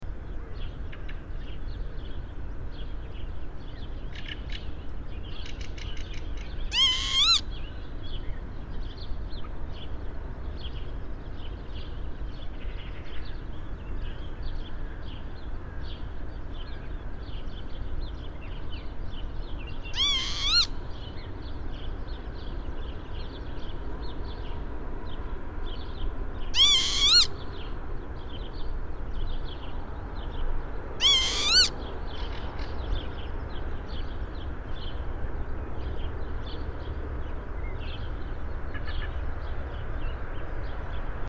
PFR03606, 090531, Northern Lapwing Vanellus vanellus, alarm call, Bergen, Norway